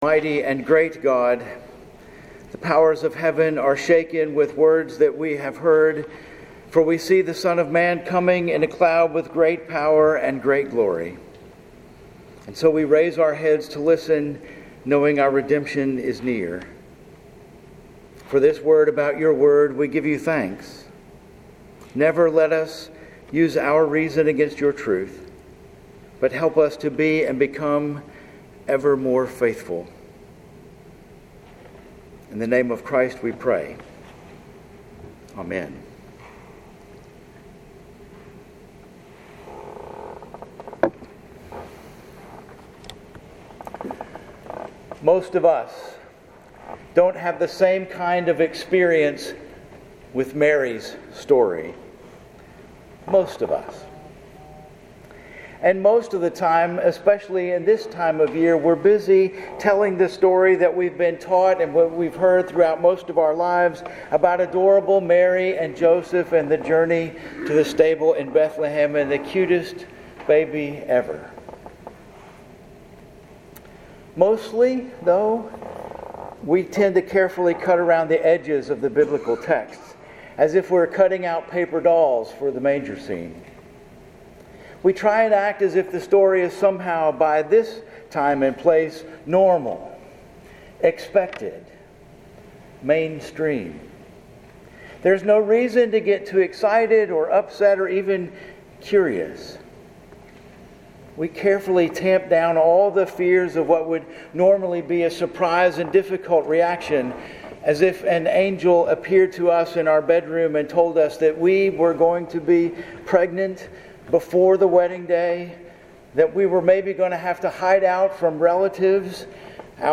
Sermons at First Presbyterian Church El Dorado, Arkansas